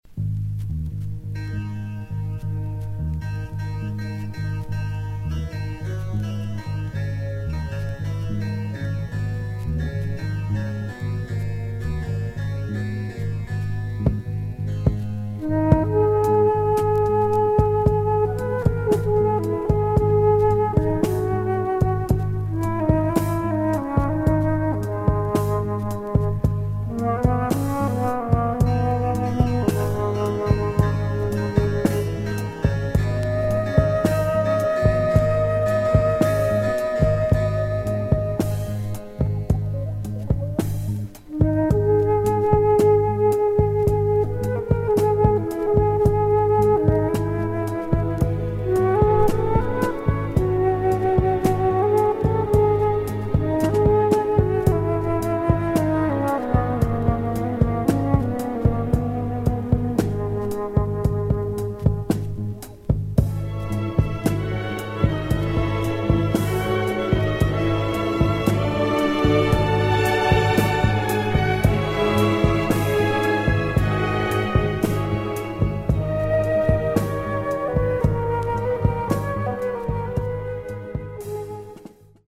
Killer abstract groove and samples